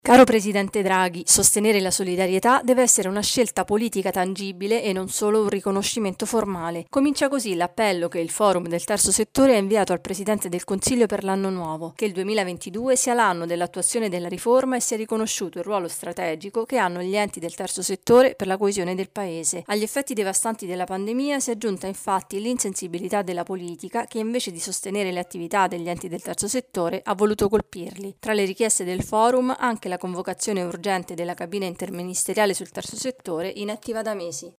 Noi ci siamo. Il Forum del Terzo Settore chiede al Presidente del Consiglio un cambio di passo e un sostegno concreto per la solidarietà.